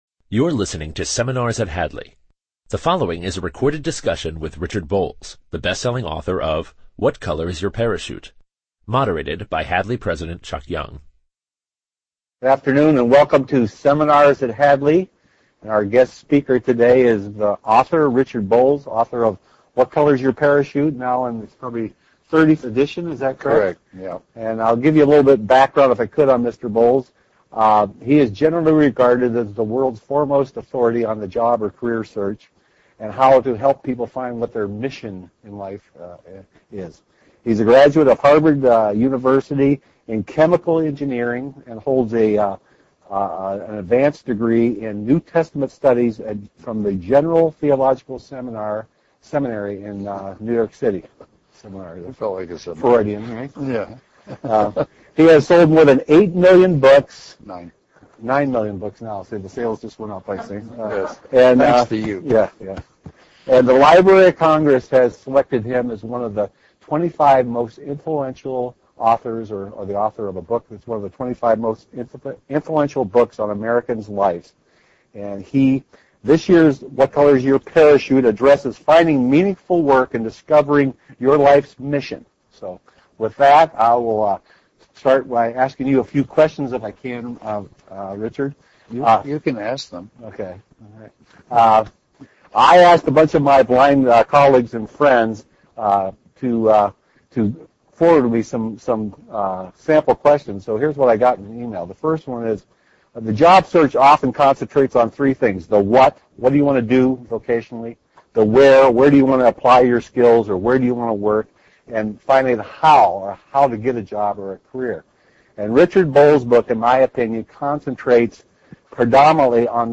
In this seminar provided by the Hadley School for the Blind, the following will be discussed:
Listen to Mr. Bolles field questions on finding a successful career, disabilities in the workplace and connecting to your spiritual life.